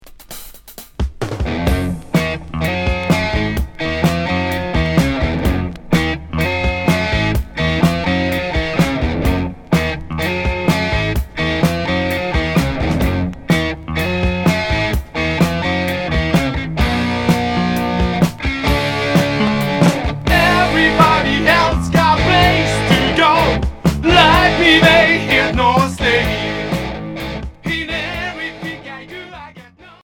Rock 70's